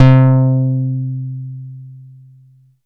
ANALOG 2 3.wav